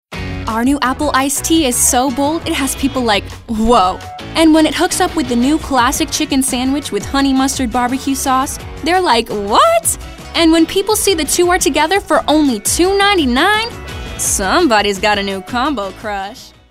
animated, attitude, confident, cool, high-energy, perky, teenager, upbeat, young, younger